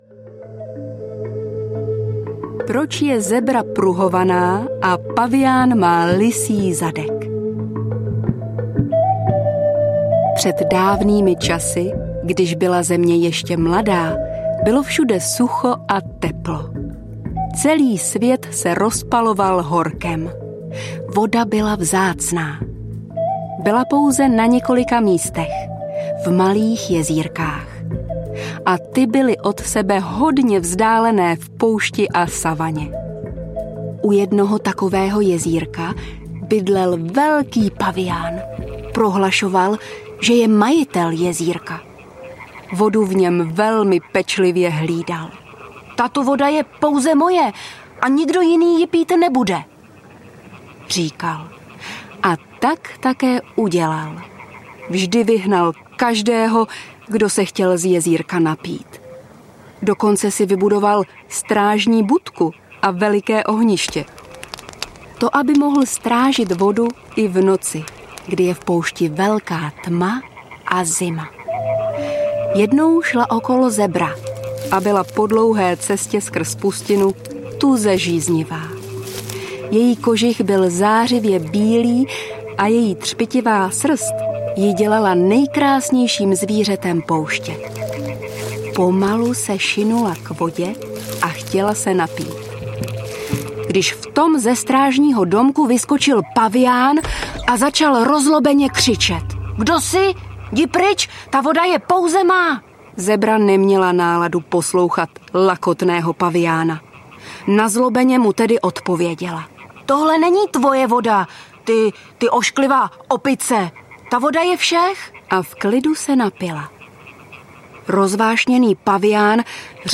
Audiokniha Do Afriky nejen za pohádkou - je plná afrických pohádek, skutečných příběhů z Afriky a africké hudby!
Ukázka z knihy
do-afriky-nejen-za-pohadkou-audiokniha